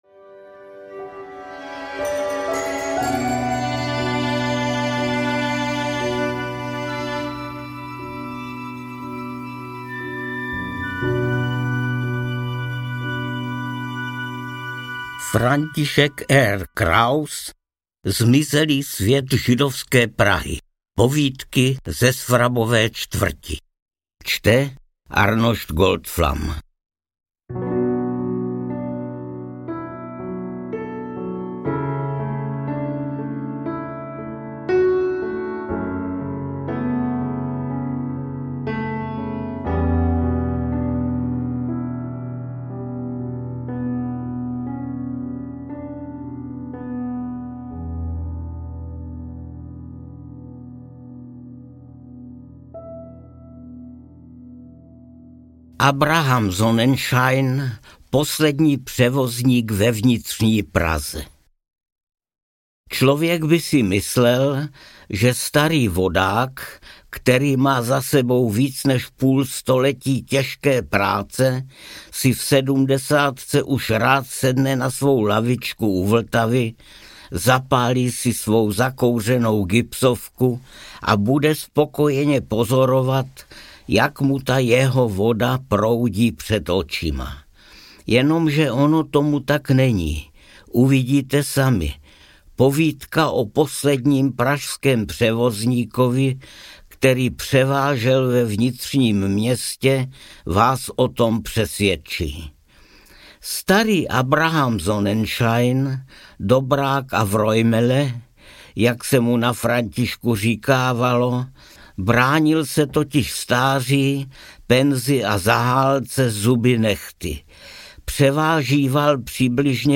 Zmizelý svět židovské Prahy: Povídky ze Svrabové čtvrti audiokniha
Ukázka z knihy
• InterpretArnošt Goldflam